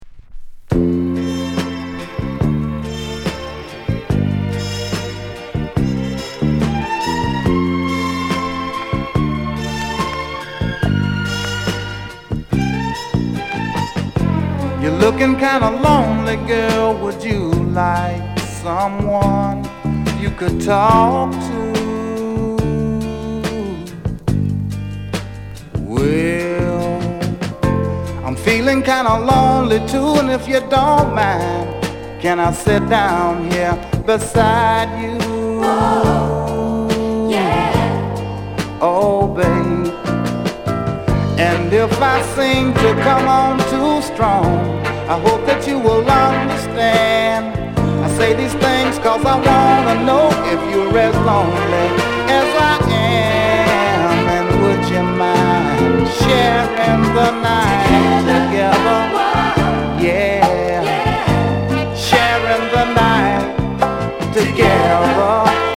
Sound Condition VG
JAMAICAN SOUL